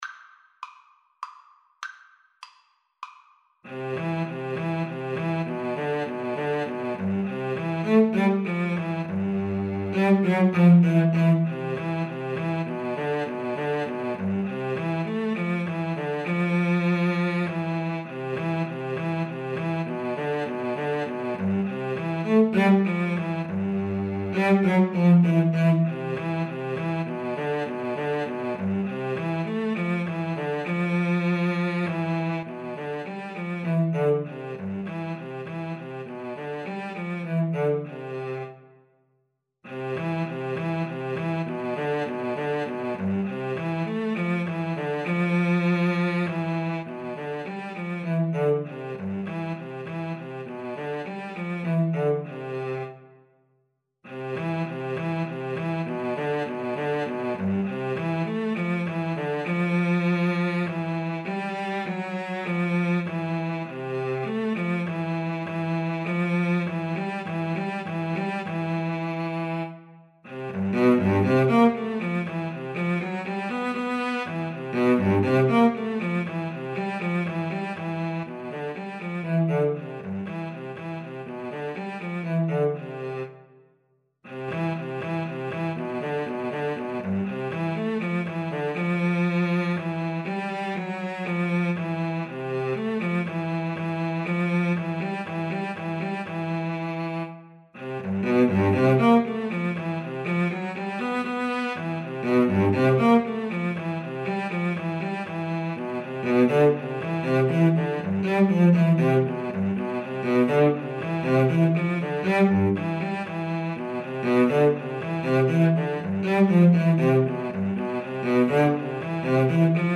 3/4 (View more 3/4 Music)
Cantabile
Classical (View more Classical Violin-Cello Duet Music)